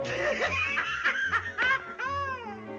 Sound Buttons: Sound Buttons View : Tom Laugh